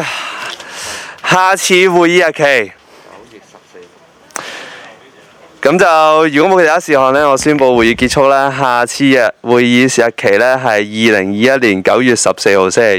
區議會大會的錄音記錄
屯門區議會會議室